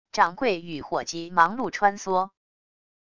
掌柜与伙计忙碌穿梭wav音频